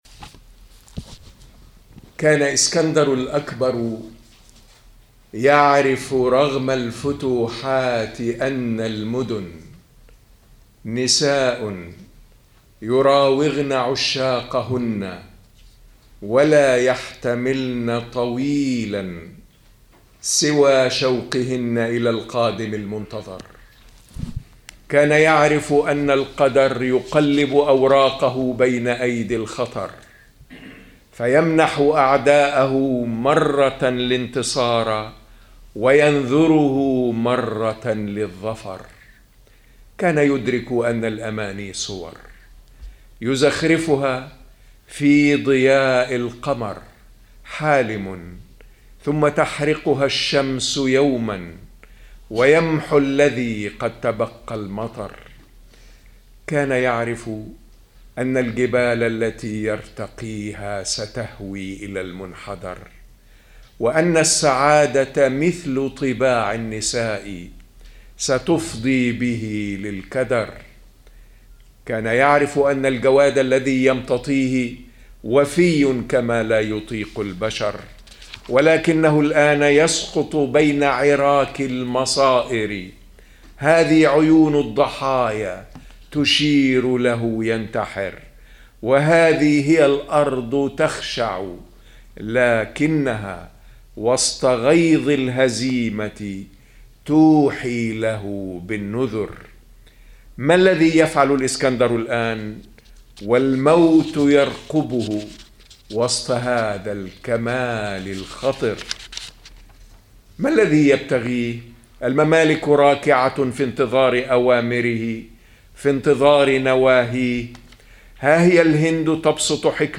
قصيدة للشاعر المصري محمد إبراهيم أبو سنة ألقاها خلال أمسية شعرية نظمت في مدينة جنيف في 28 مايو 2008، وفيها يقارن بين الفتوحات العسكرية وجهود بناء الحضارات، وينتصر فيها للمهمة الثانية.